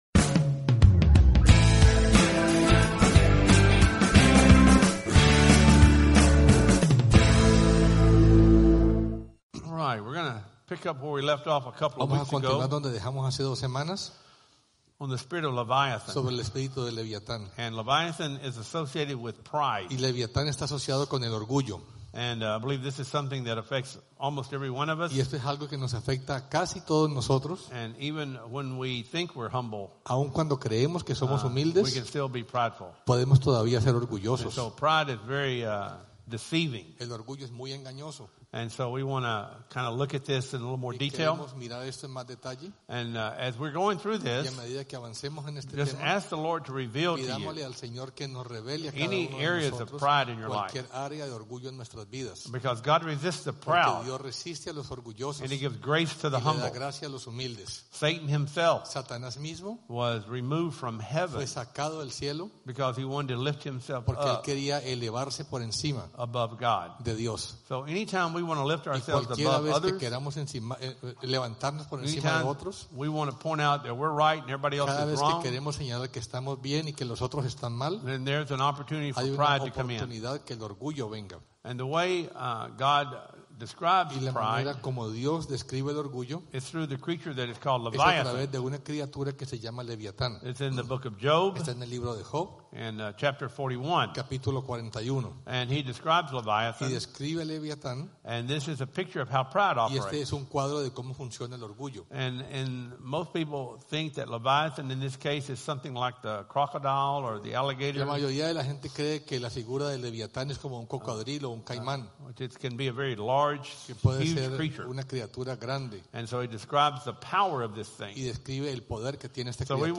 Sunday 2016 Services Service Type: Sunday Service « Mother’s Day Leviathan